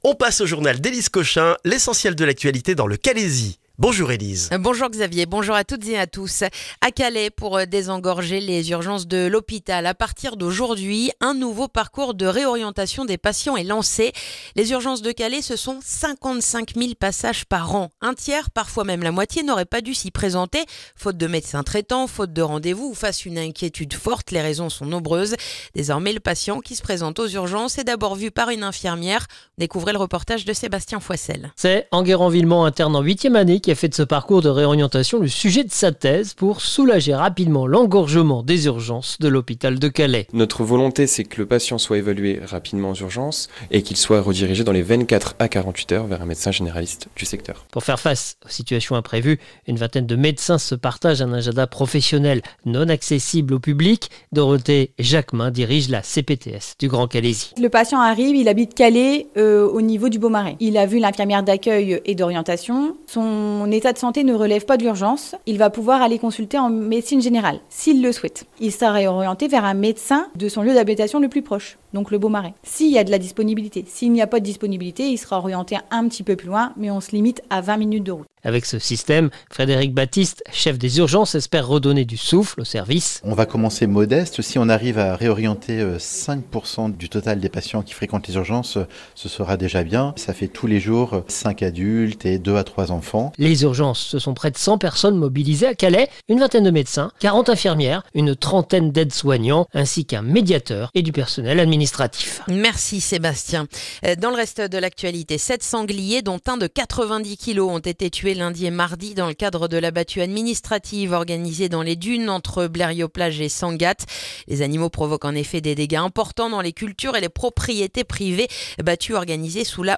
Le journal du mercredi du 4 février dans le calaisis